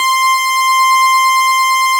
snes_synth_072.wav